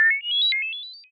computer_d.wav